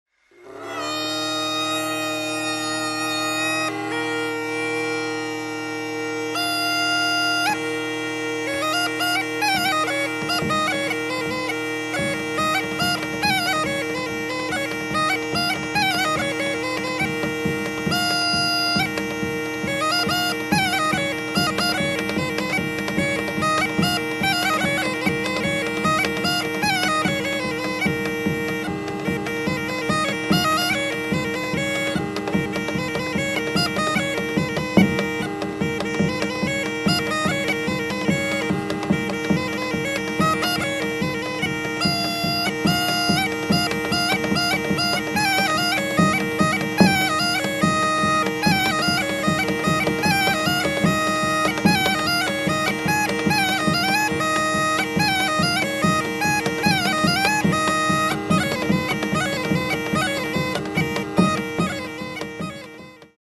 Γκάϊντα ή Πηδηχτος Άναξος
οργανικό μαζωμένος χορός
γκάιντα
νταουλάκι